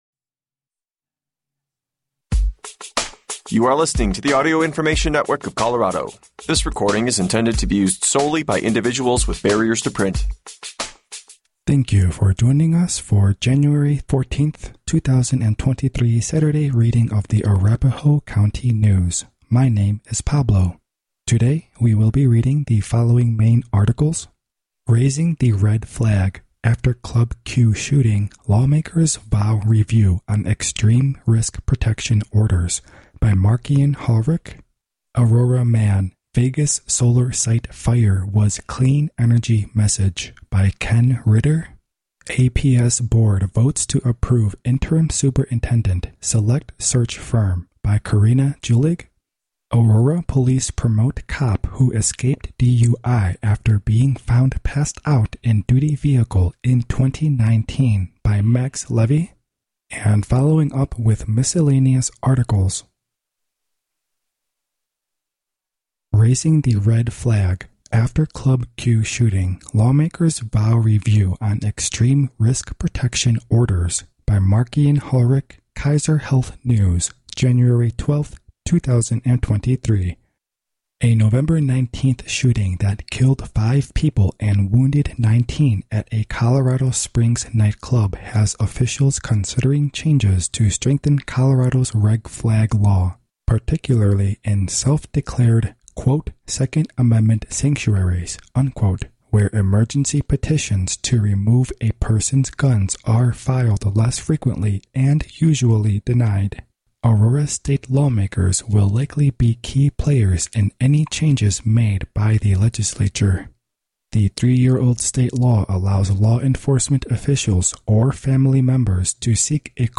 Arapahoe County News in audio, weekly. Content is read from Aurora Sentinel, Centennial Citizen, and